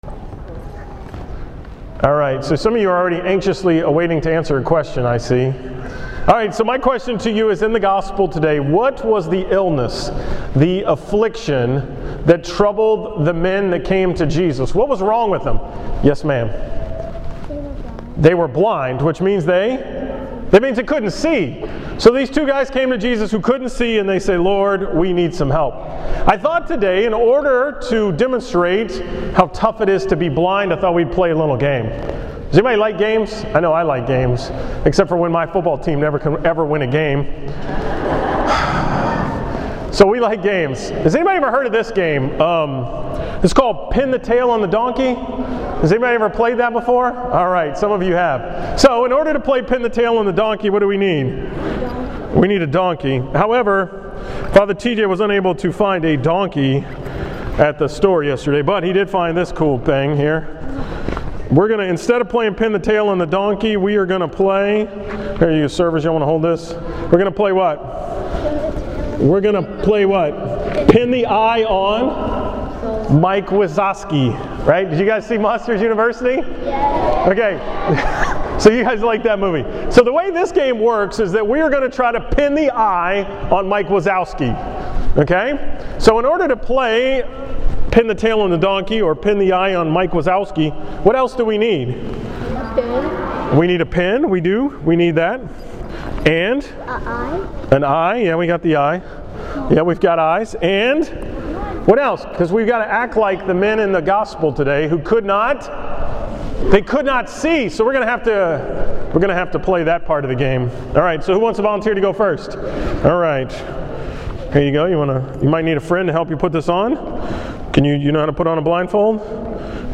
From the school Mass on Friday, December 6, 2013
Category: 2013 Homilies, School Mass homilies